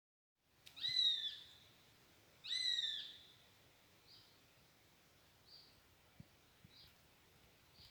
Zvirbuļvanags, Accipiter nisus
StatussTikko šķīlušies mazuļi vai vecāki ar mazuļiem (RM)